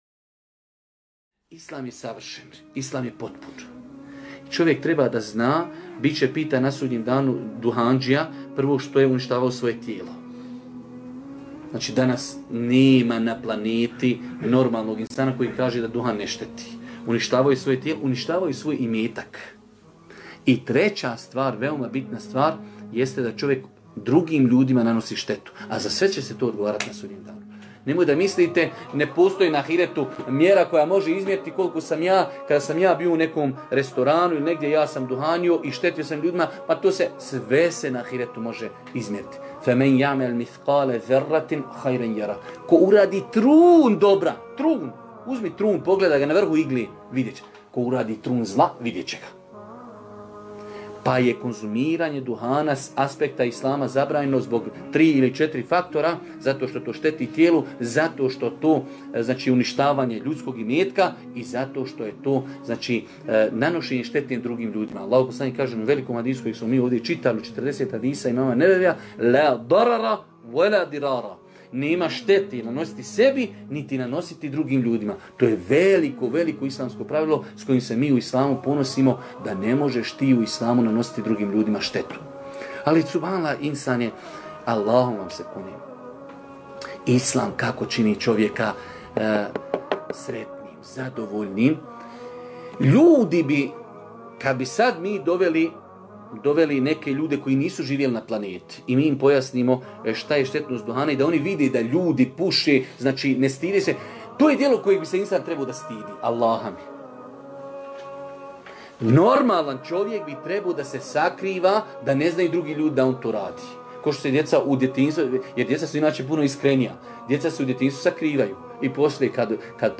Poslušajte isječak iz predavanja